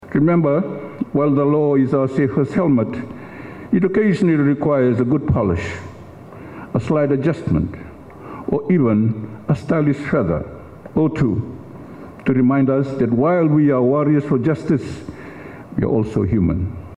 This was the message by Minister for Home Affairs Pio Tikoduadua while opening the Fiji Law Society Annual Convention in Cuvu, Sigatoka today.